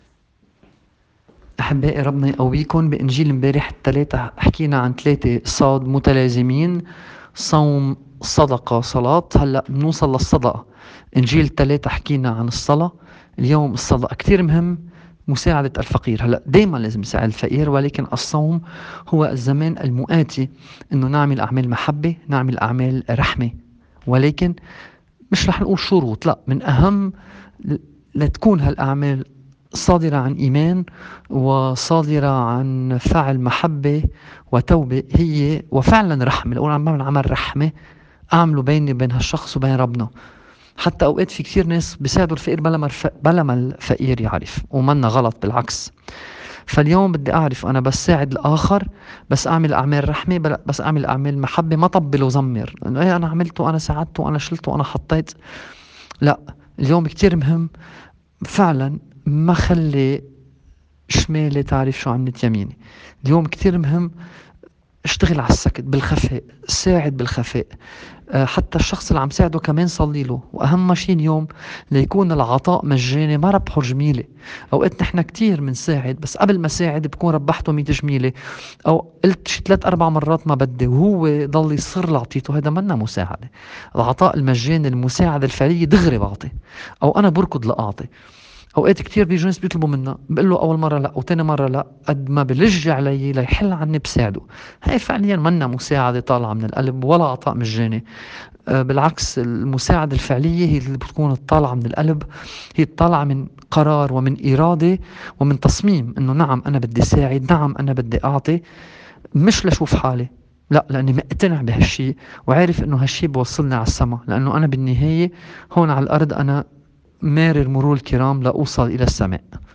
تأمّل في إنجيل اليوم